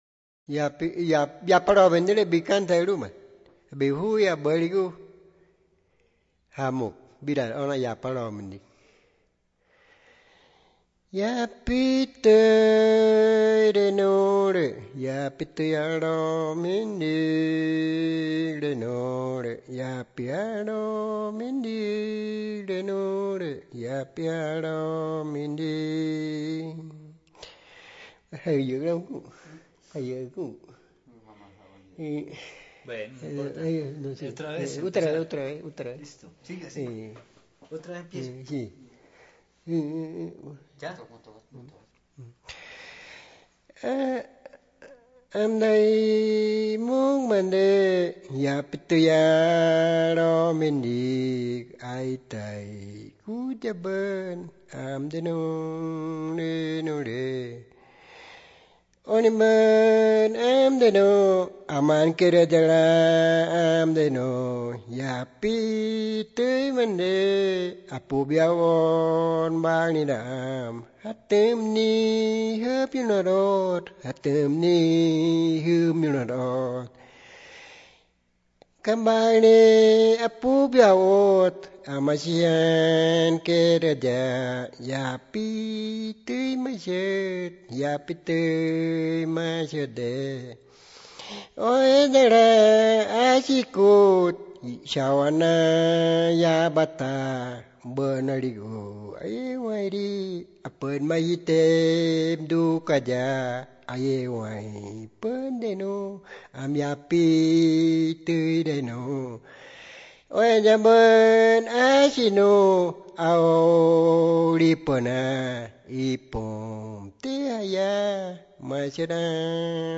Piedra Alta, Medio Inírida, Guainía (Colombia)
The recording, transcription, and translation of the song took place between March and April 2001 in Piedra Alta; a subsequent studio recording was made in Bogotá in 2004.
Canciones Wãnsöjöt